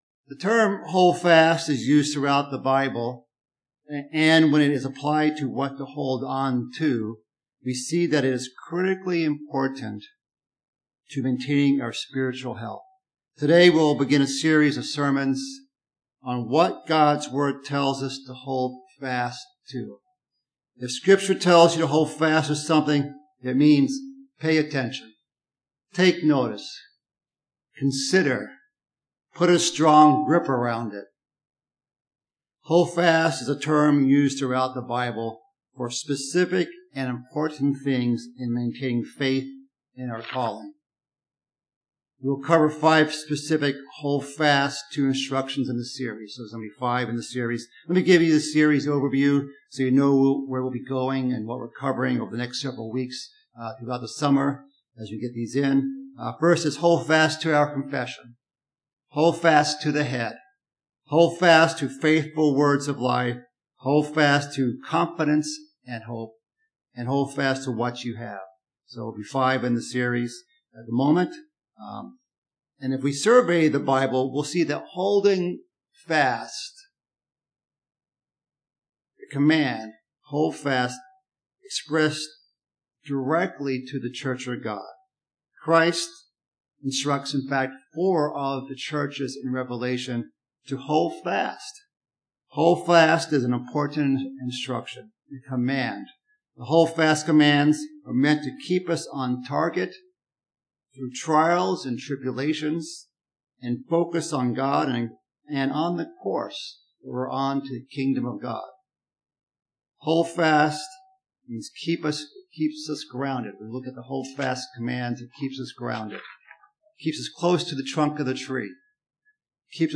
Part one in a sermon series about things we're instructed to "hold fast."